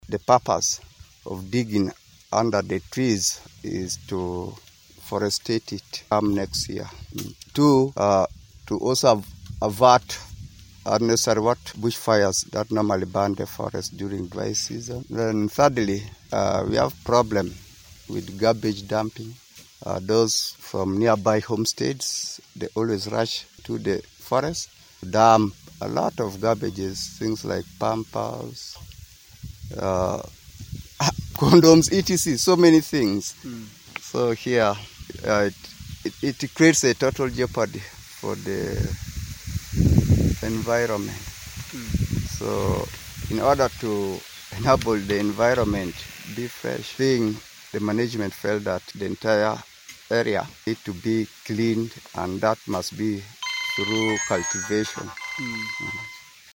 Speaking to Dailywestnile